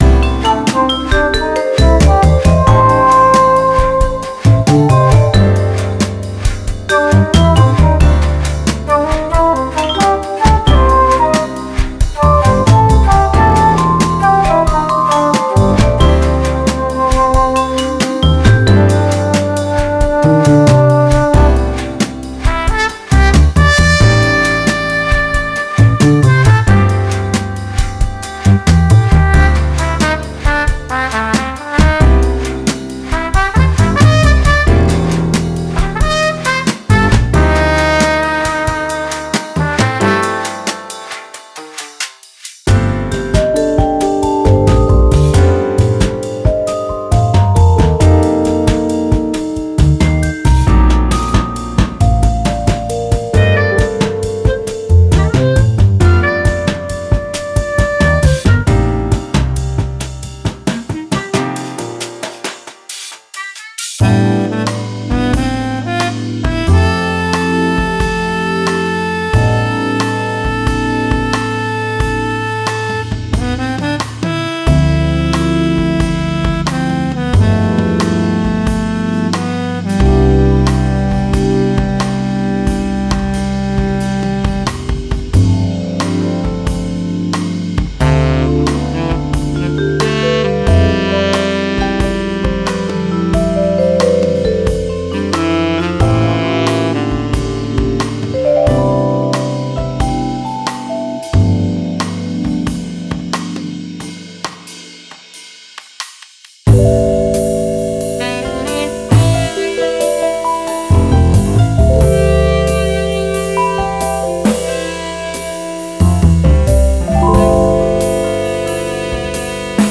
Construction Kits